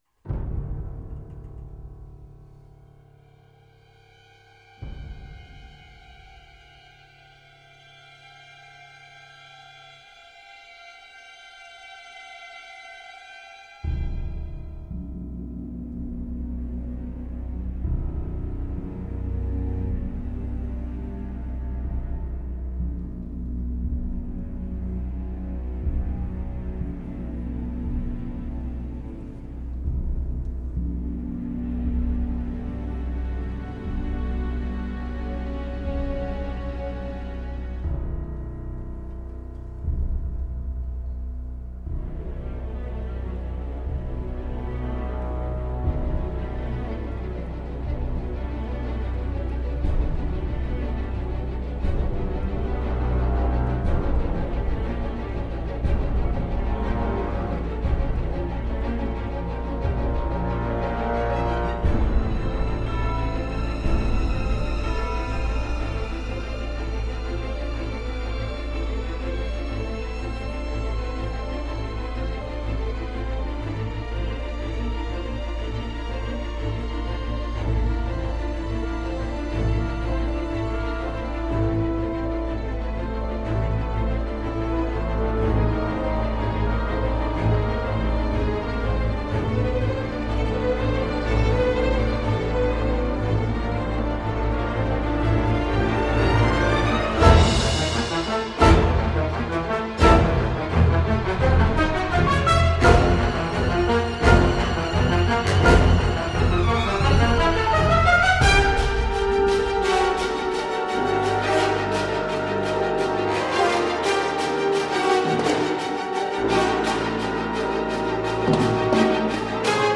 musique orchestrale d’aventures à l’ancienne
un orchestre ample et déchaîné